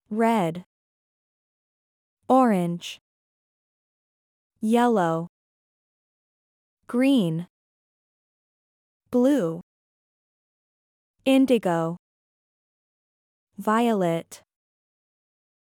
Как произносятся цвета на английском: